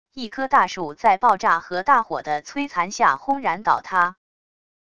一颗大树在爆炸和大火的摧残下轰然倒塌wav音频